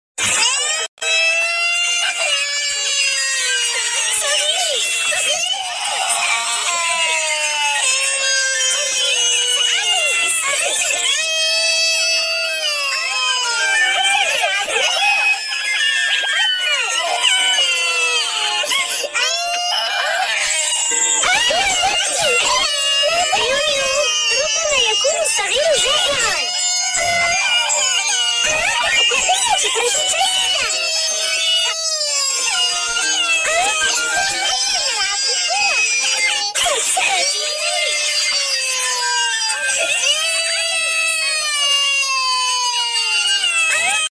crying sounds are annoying and sound effects free download